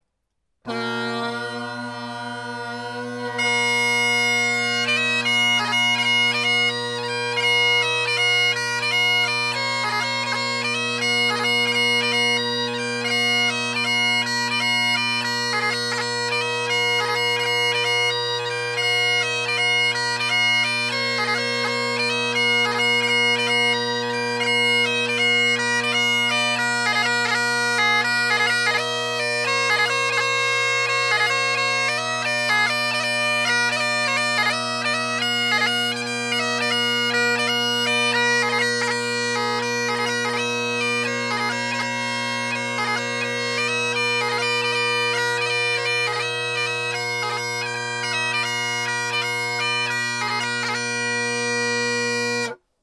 Drone Sounds of the GHB, Great Highland Bagpipe Solo
In the recordings below I set the high A just a tad flat as I’m lazy and taped the high G, F, and E. Note the C isn’t flat, even from the get go, which is cool.
Bonnie Dundee – AyrFire chanter – MacLellan reed – MacPherson bagpipes – Kinnaird tenor reeds – Henderson Harmonic Deluxe bass